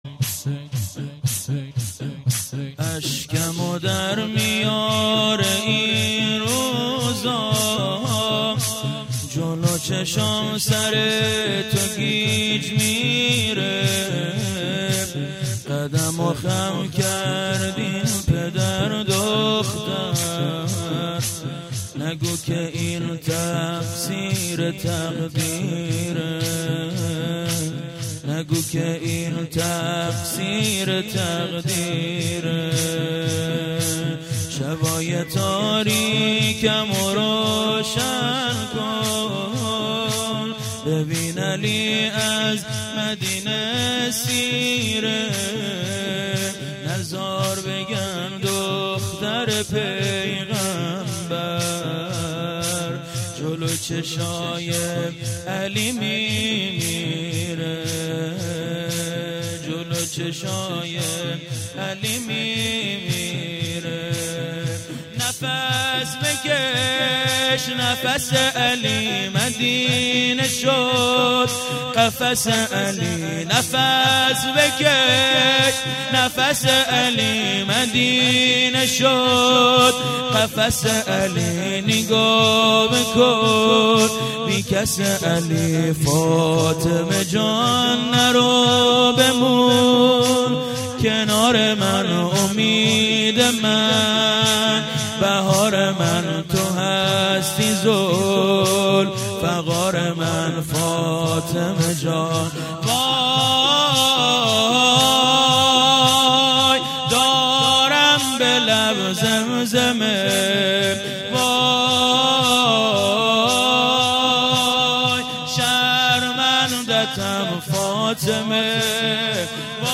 مجمع رهروان حضرت زینب (س)آبادان|اشکمو درمیاره این روزا